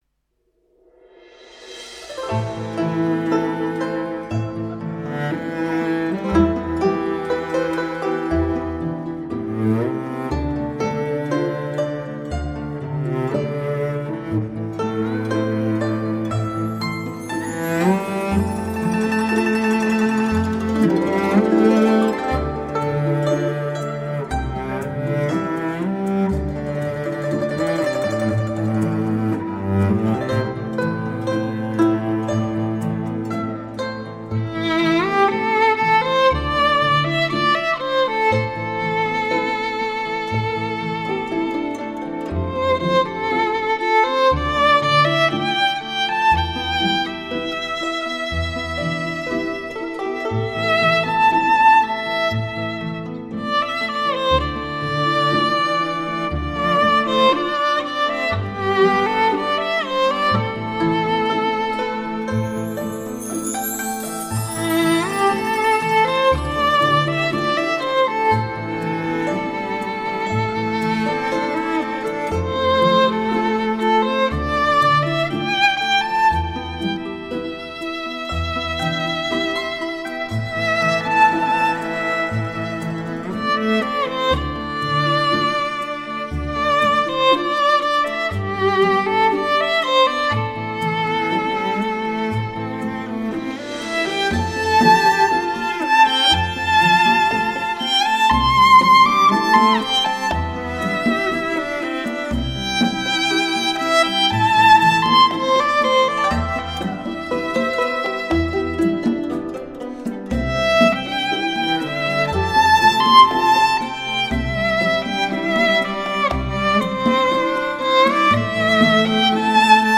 雪域高原的动听旋律 唯美抒情的音乐篇章
小提琴演奏音色优美 情感细腻 极富歌唱性